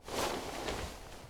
action_open_inventory_2.ogg